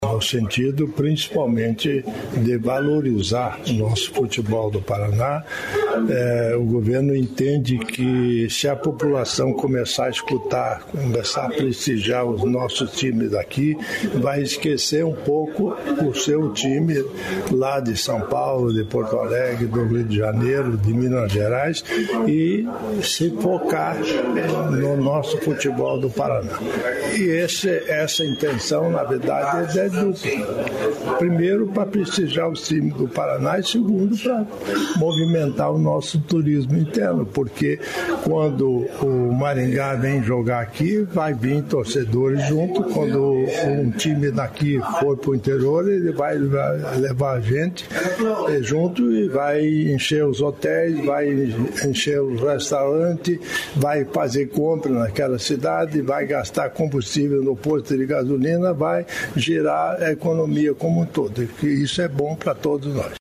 Sonora do vice-governador, Darci Piana, sobre parceria para transmissão do Campeonato Paranaense | Governo do Estado do Paraná
Sonora do vice-governador, Darci Piana, sobre parceria para transmissão do Campeonato Paranaense